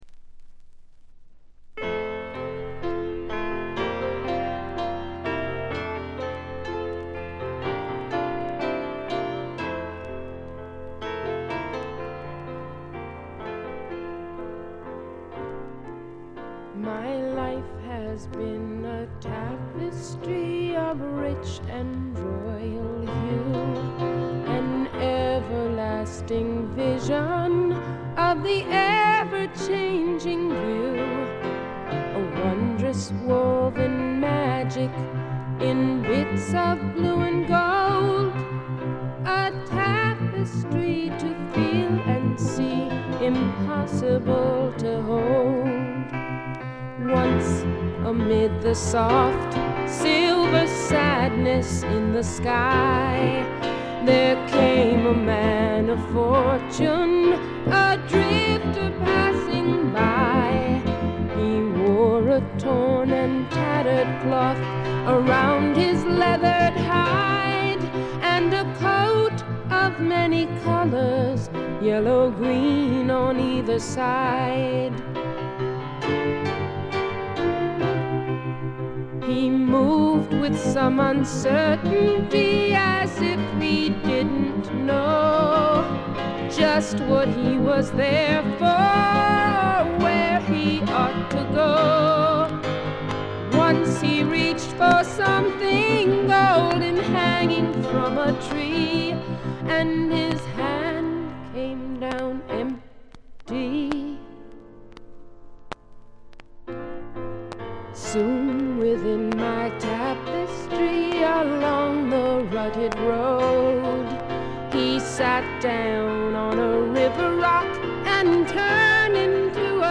B5中盤で周回ノイズ。
試聴曲は現品からの取り込み音源です。